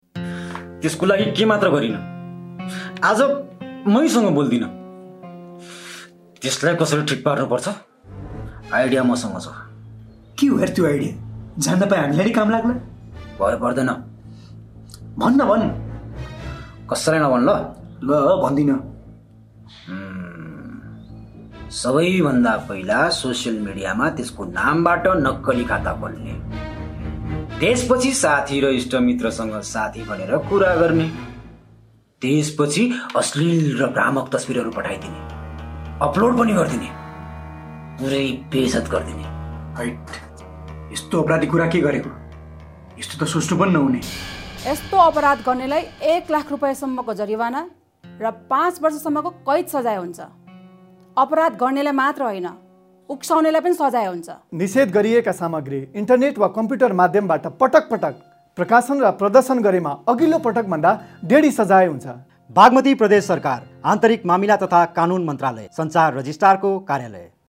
साइबर सुरक्षा सम्बन्धी जागरणयुक्त रेडियोमा प्रसारण गर्ने सामग्री